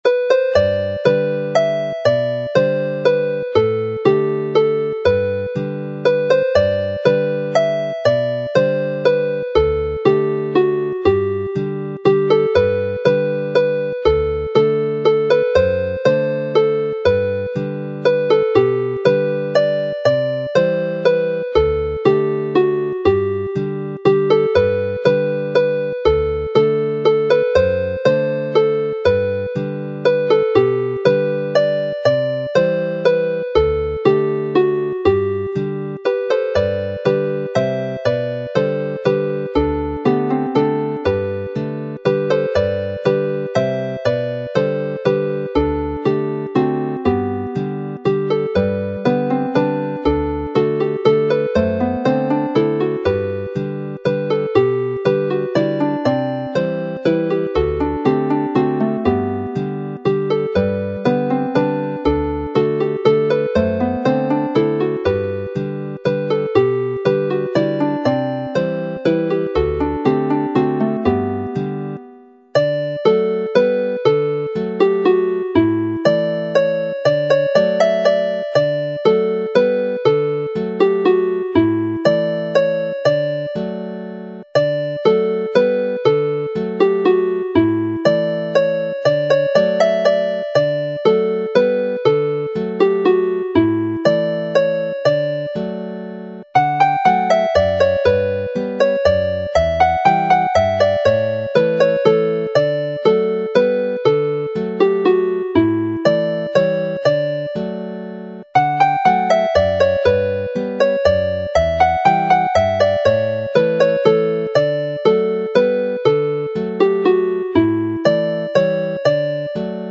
This set of waltzes